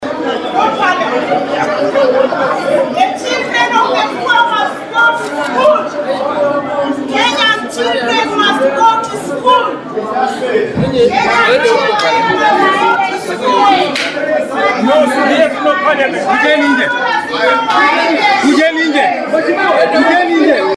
Kizazaa chashuhudiwa bungeni wabunge wakiandaa matembezi kuteta kuhusu fedha za hazina ya CDF
Wabunge hao ambao wanatarajiwa kuendelea na mapumziko siku ya Alhamisi, walimaliza kikao cha Baraza kabla ya wakati wake na kuanza kuimba nyimbo.